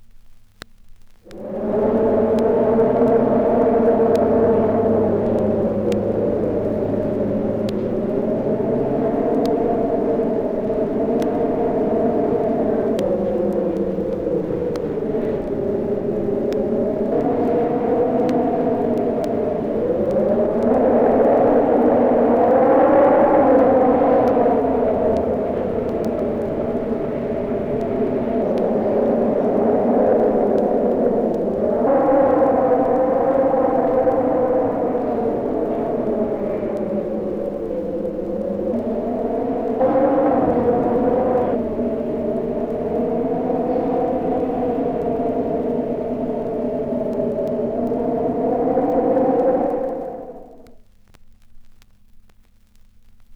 • weird wind - horror effect vinyl.wav
Recorded from Sound Effects - Death and Horror rare BBC records and tapes vinyl, vol. 13, 1977.
weird_wind_-_horror_effect_vinyl_3Tx.wav